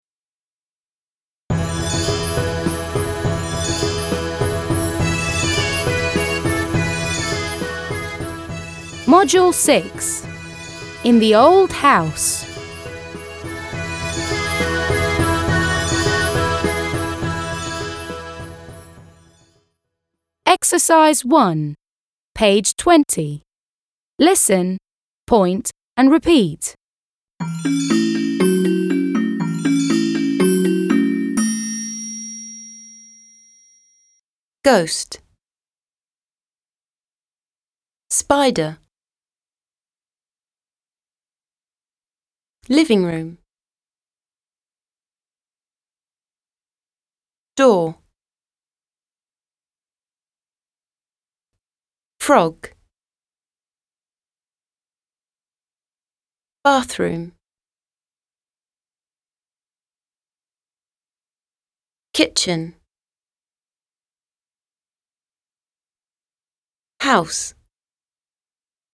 1. Listen, point and repeat. – Послушай, укажи и повтори.
• ghost – [гоуст] – привидение;
• spider – [спайдэ:] – паук;
• living room – [ливин ру:м] – гостиная;
• frog – [фрог] – лягушка;
• kitchen – [кичин] – кухня.